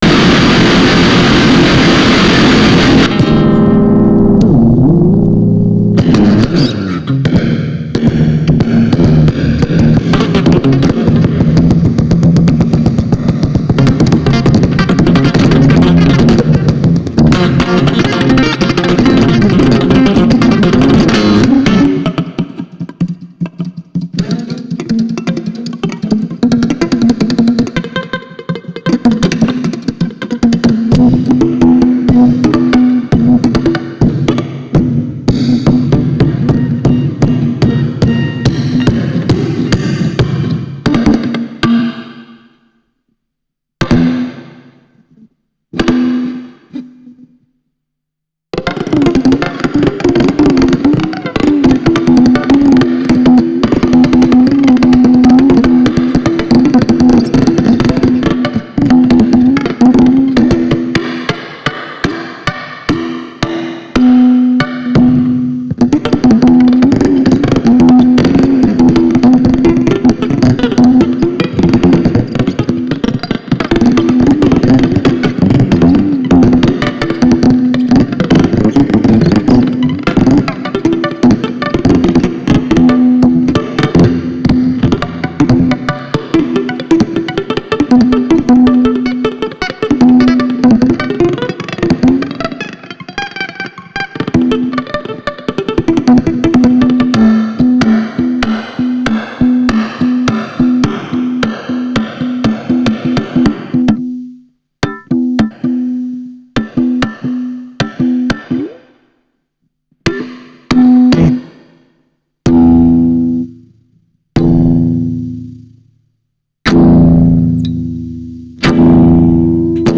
演奏会の実況録音作品です。
録音は完全にステレオ・マイクによる収録のみでおこなわれ、ライン信号は一切使用されていません。
その夜、演奏されたままの音で、編集時の加工はほんの僅かな音質補正のみです。
もちろんオーバーダビングもありません。